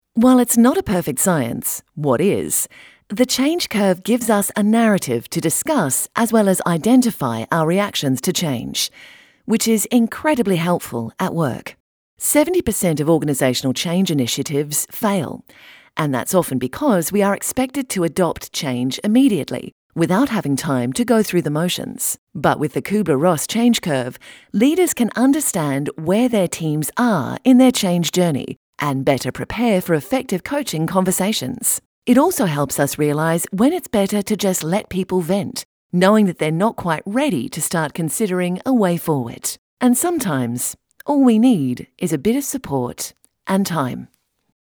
Explainer & Whiteboard Video Voice Overs
English (Australian)
Adult (30-50)